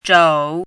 “肘”读音
zhǒu
肘字注音：ㄓㄡˇ
国际音标：tʂou˨˩˦
zhǒu.mp3